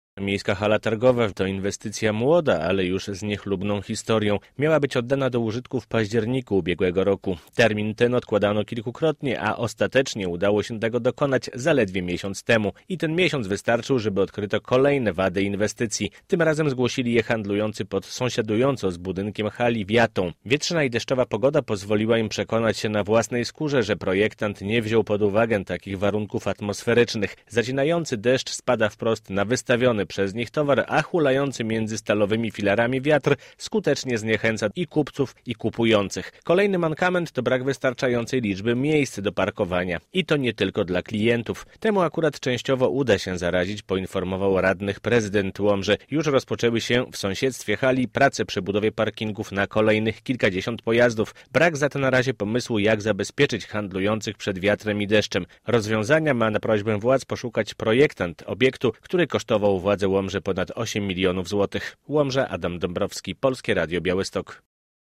relacja
Nieprzemyślana konstrukcja, brak parkingów i zbyt wysokie czynsze - łomżyńscy handlowcy nadal zgłaszają uwagi w sprawie nowej hali targowej. Tym razem temat ten pojawił się na sesji rady miejskiej.